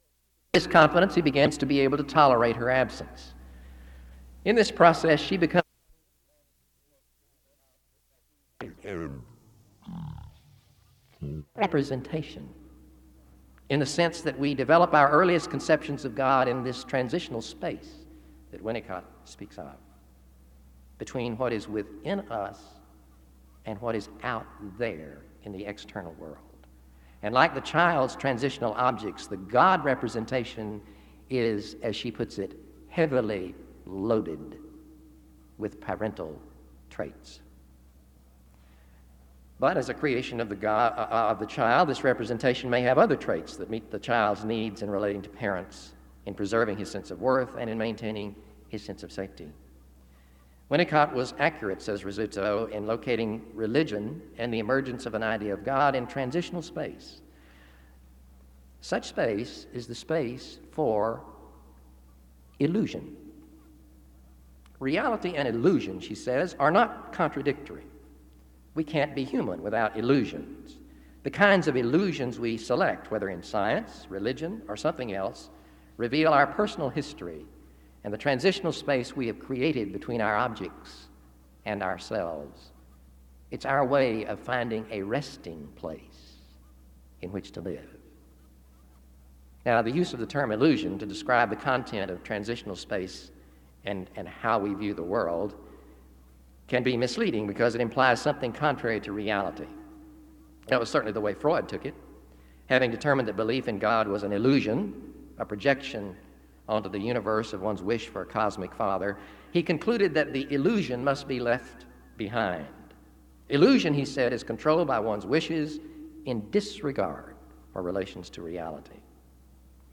SEBTS Adams Lecture
SEBTS Chapel and Special Event Recordings